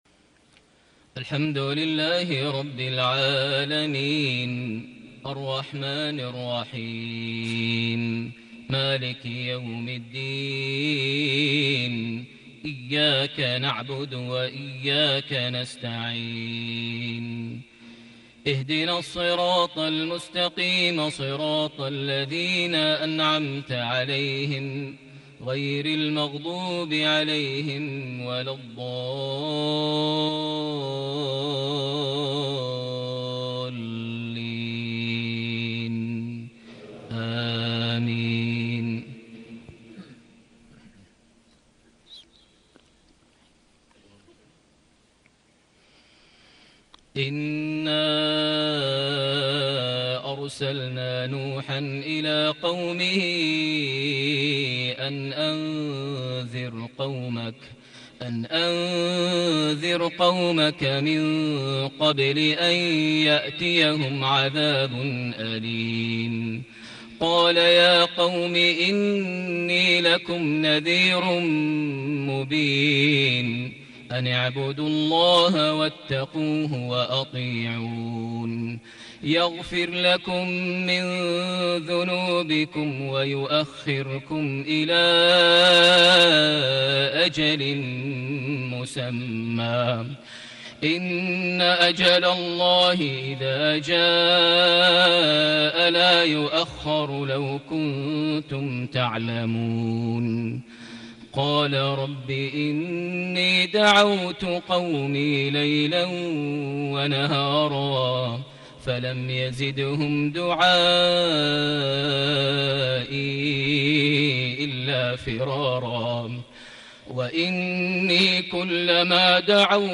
صلاة العشاء ٢٧جماد الآخر ١٤٣٨هـ سورة نوح > 1438 هـ > الفروض - تلاوات ماهر المعيقلي